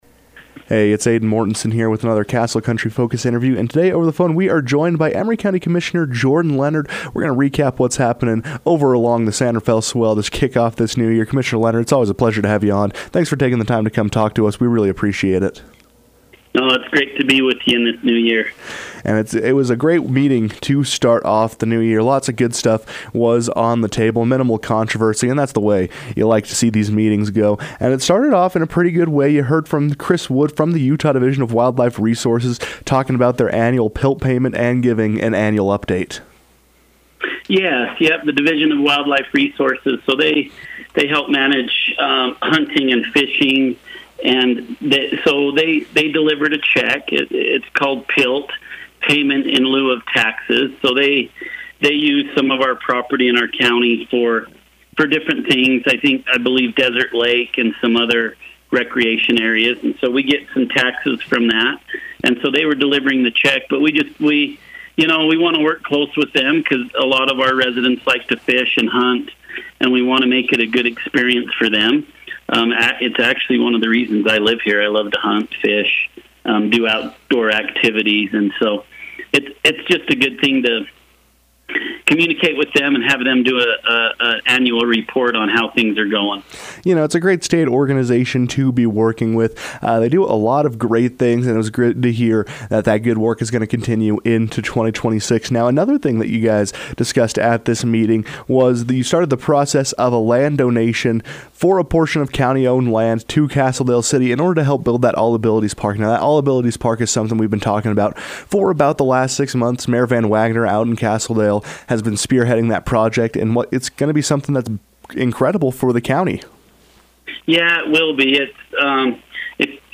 PILT Funds, land donations and general plan: Emery County Commissioner Jordan Leonard provides biweekly update on the state of the county
As the calendar turns to the new year, the Emery County Commissioners have set their sights on developing the county into the best it can be. To discuss some of these plans and goals for 2026, Commissioner Jordan Leonard joined the KOAL newsroom to shine the spotlight on what could be coming along the San Rafael Swell.